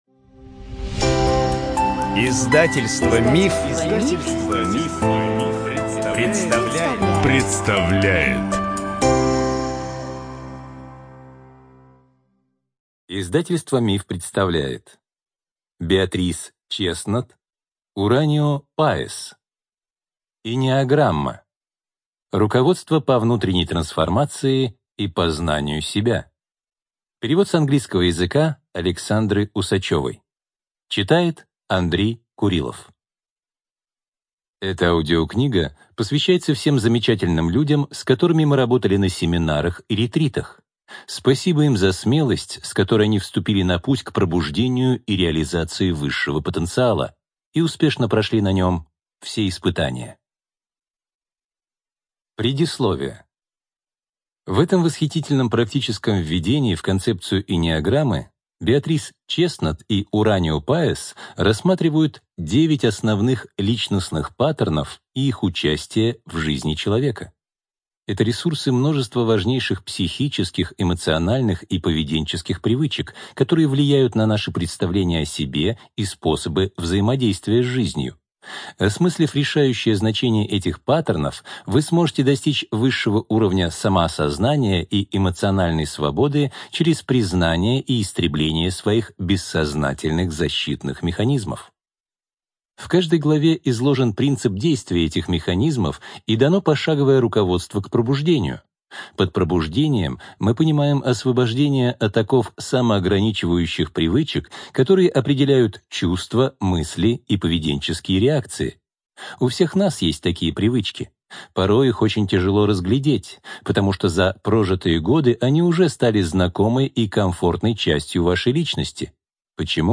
Студия звукозаписиМанн, Иванов и Фербер (МИФ)